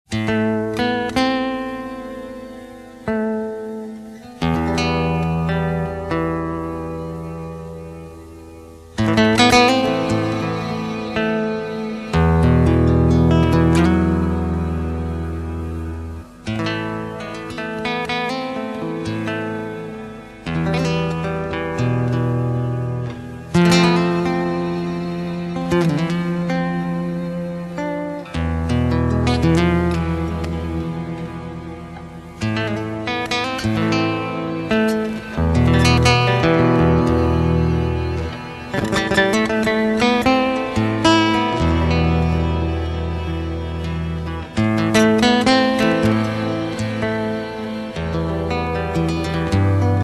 Instrumental (18)
flamenco guitar